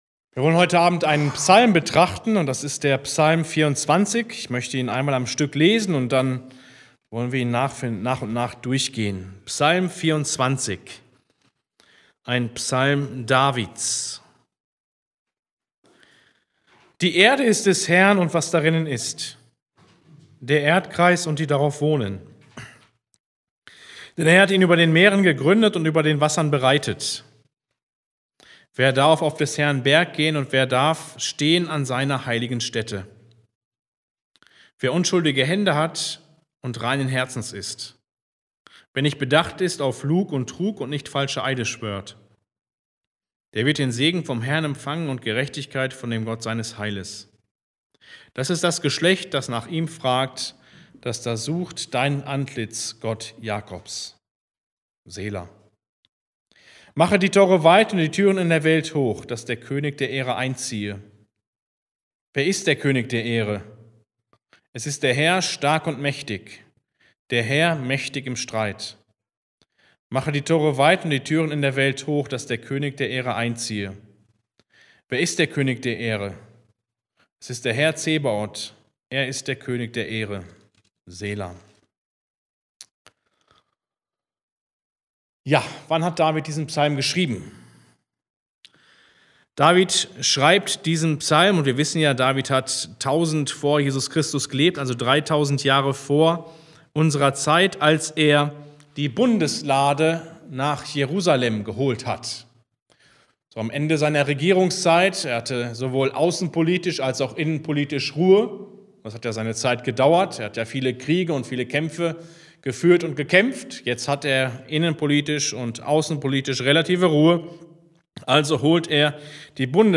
Bibelstunde